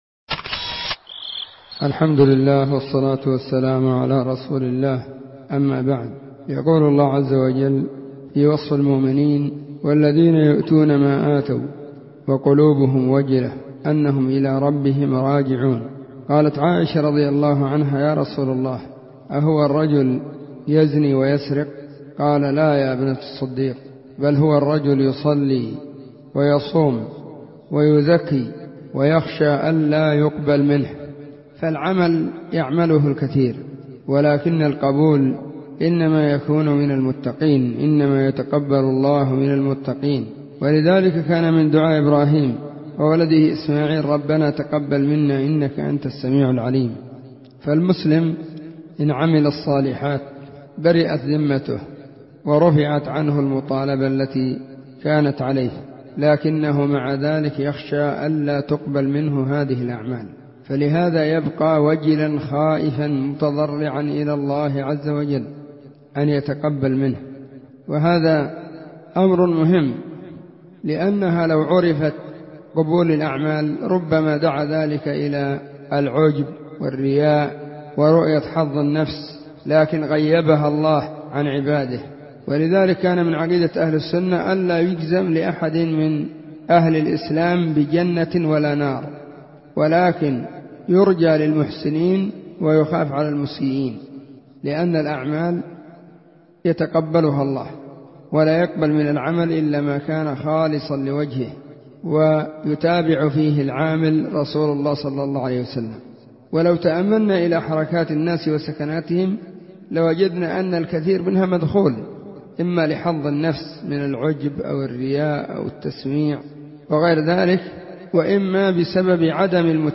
💢نصيحة قيمة بعنوان💢